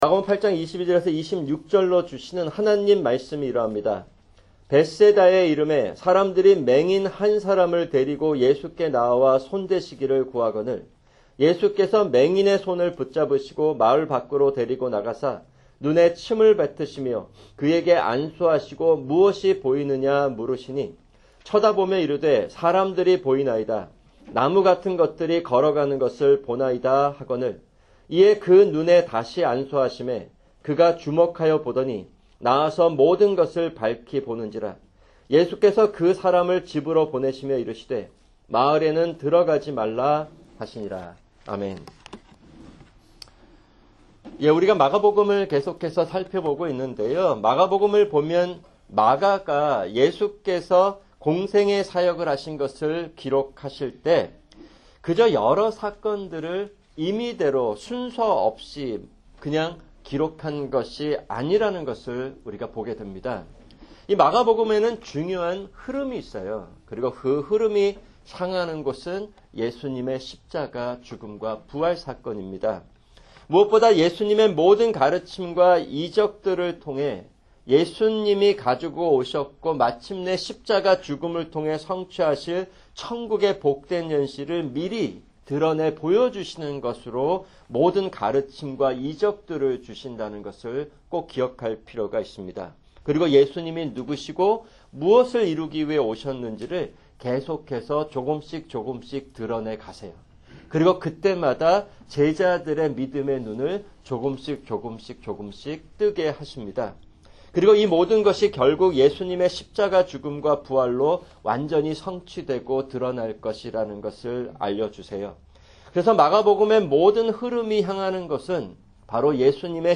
[금요 성경공부] 계시록 20:1-6(2)